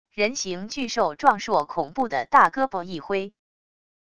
人型巨兽壮硕恐怖的大胳膊一挥wav音频